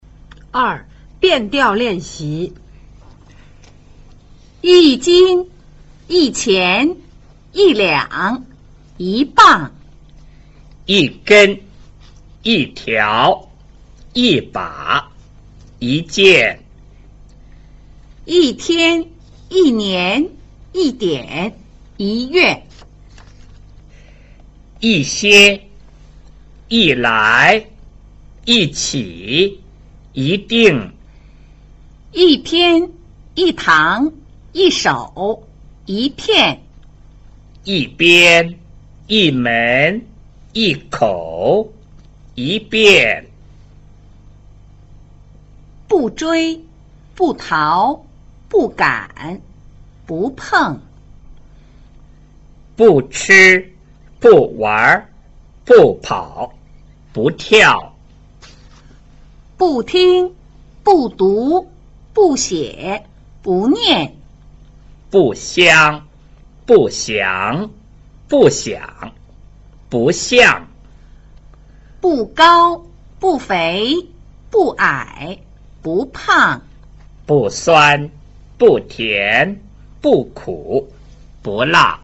1.4 變調練習